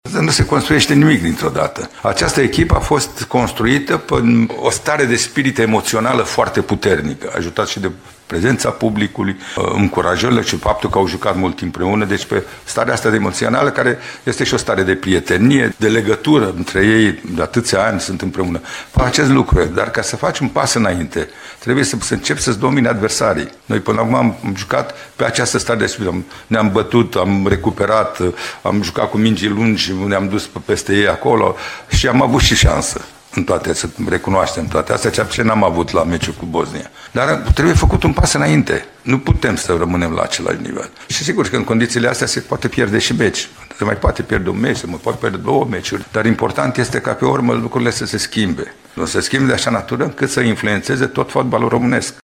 Selecționerul Mircea Lucescu a vorbit, la rândul său, despre un proces de construcție a echipei naționale: